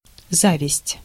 Ääntäminen
IPA: [ɑ̃.vi]